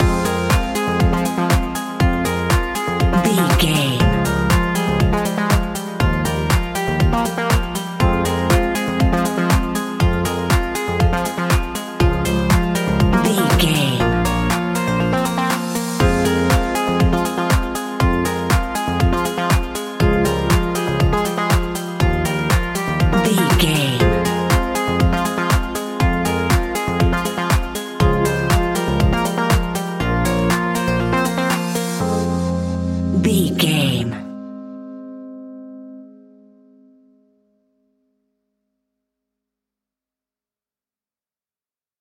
Aeolian/Minor
groovy
uplifting
driving
energetic
drum machine
synthesiser
funky house
electro
upbeat
synth bass
synth leads